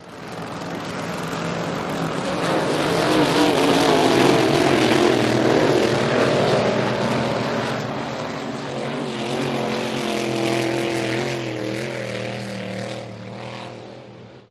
Boat Race Start Of Race